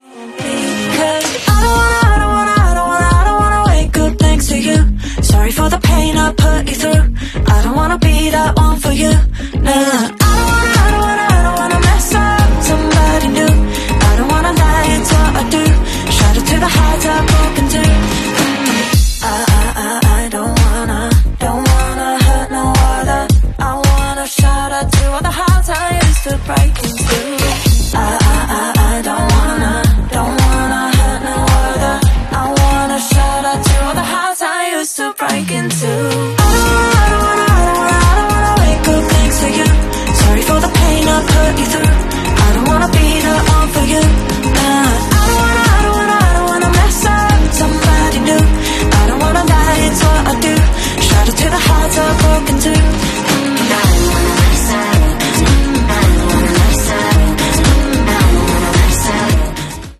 Pop, Electropop, R&B